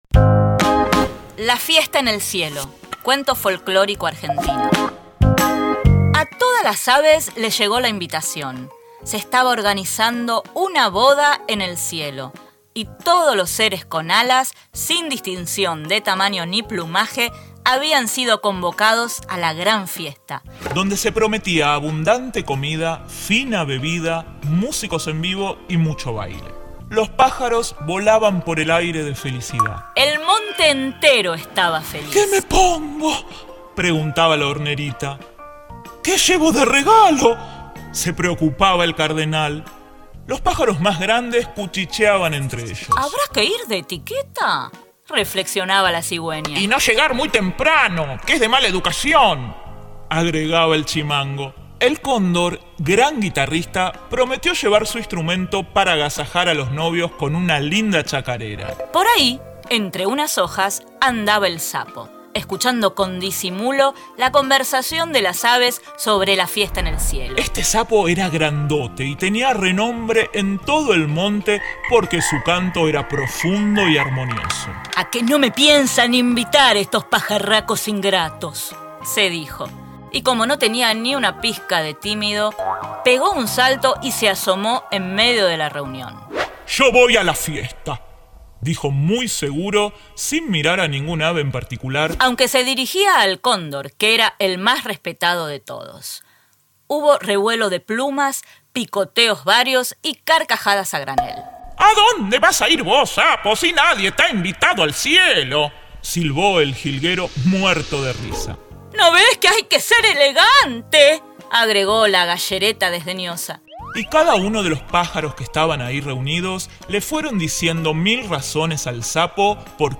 Cuentos contados: La fiesta en el cielo, cuento folclórico argentino